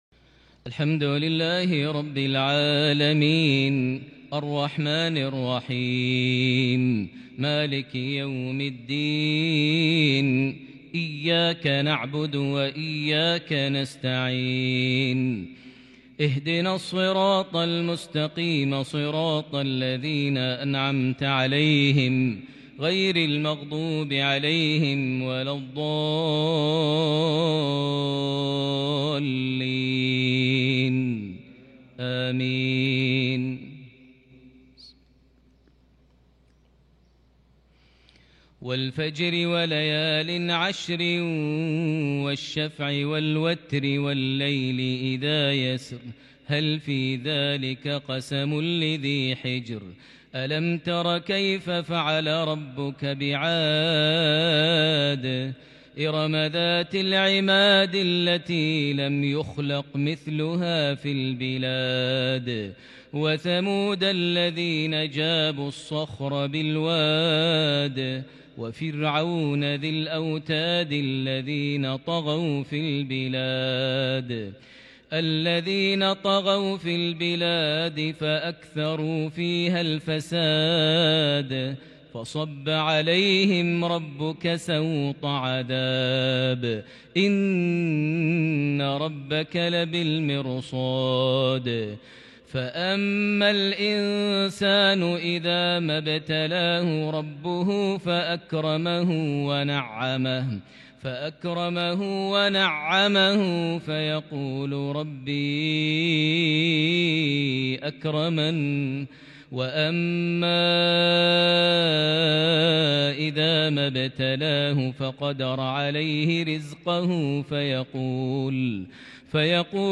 صلاة المغرب لسورة الفجر 22 جمادى الآخر 1442هـ| mghrip 4-2-2021 prayer from Surah Al-Fajr > 1442 🕋 > الفروض - تلاوات الحرمين